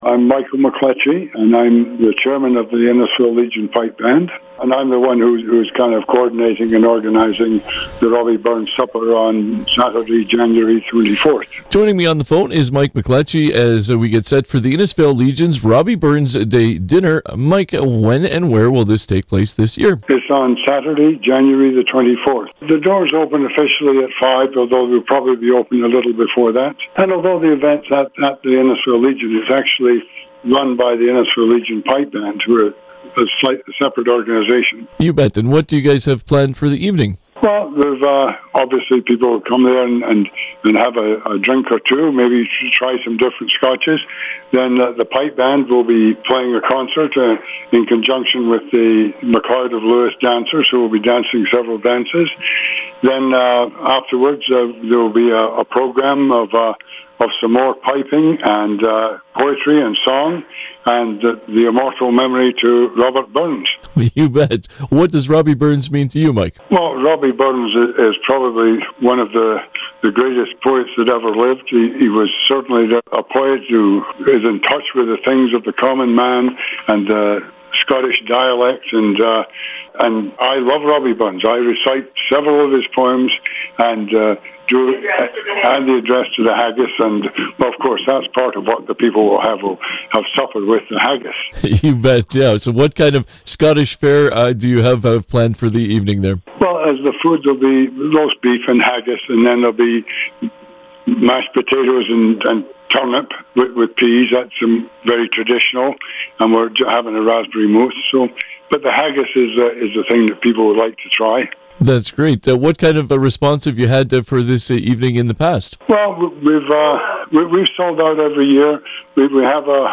Community Hotline conversation